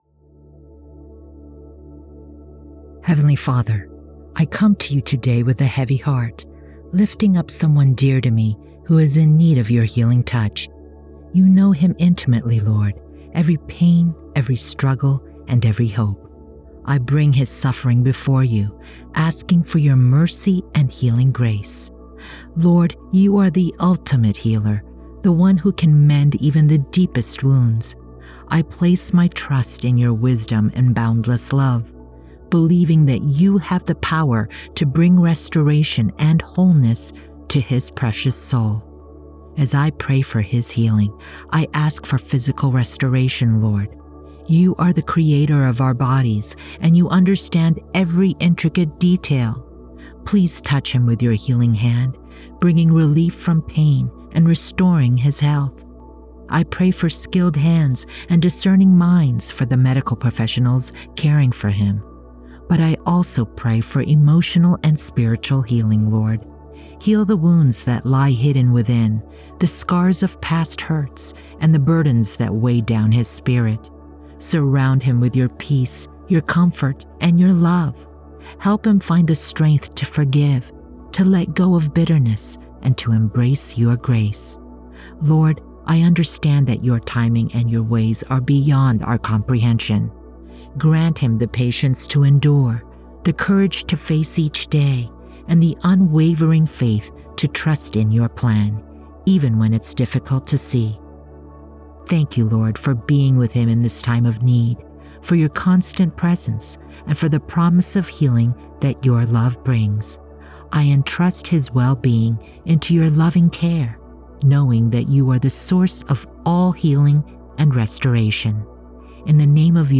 PrayerHealingHim-FIXED-WITH-BG-MUSIC-1.wav